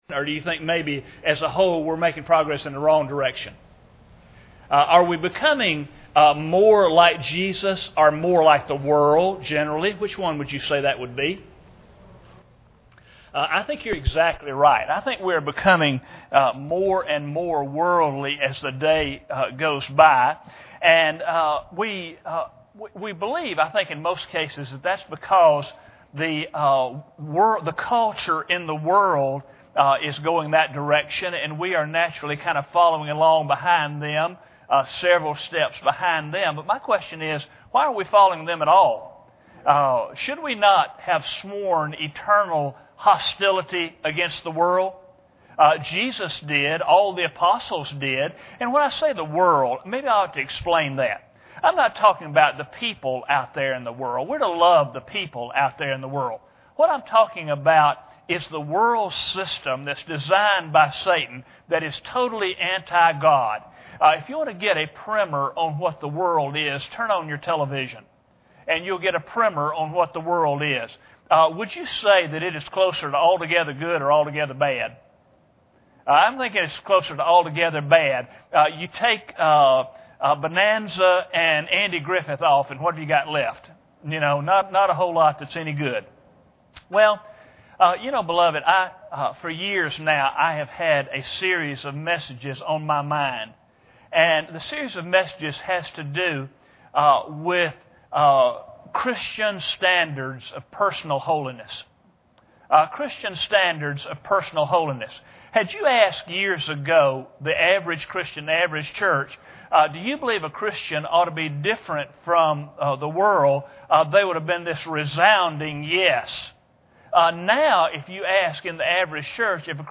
Genesis 2:25 Service Type: Sunday Evening Bible Text